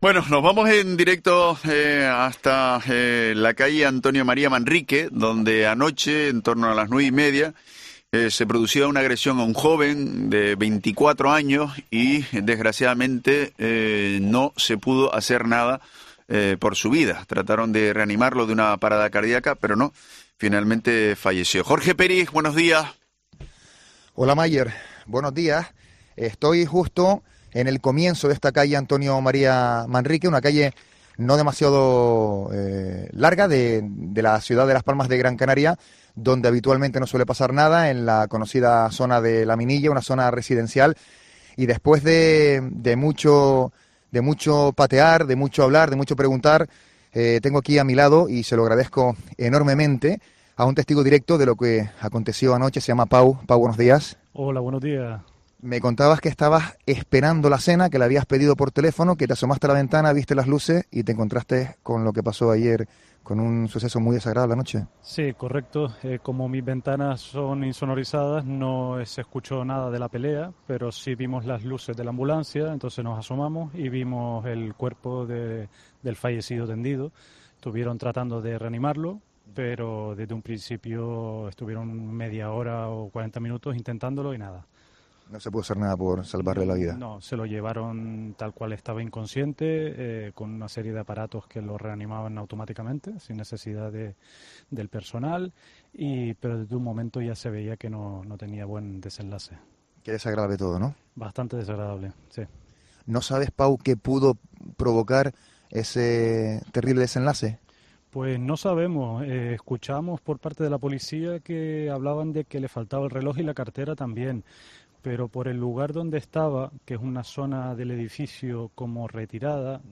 vecino de La Minilla